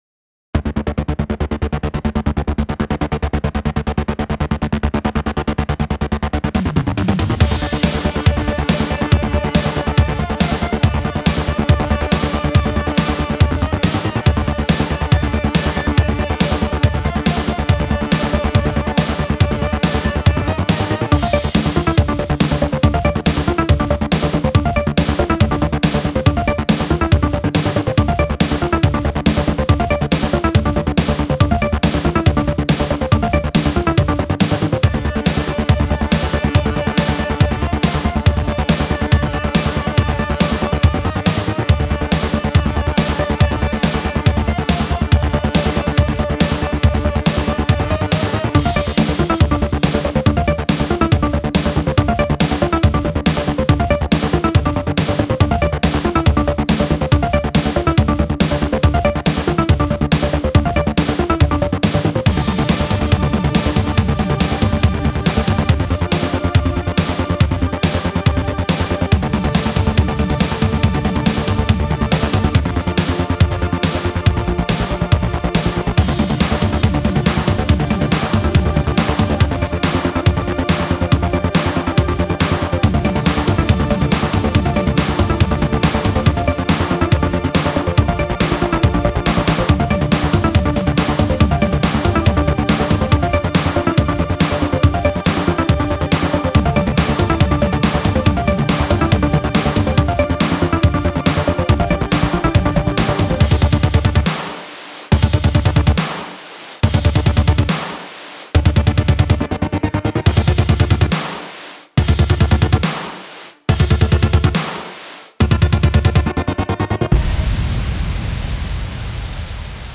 Another 80s Inspired Action Tack. This its rather fast and totaly on the synth side of things.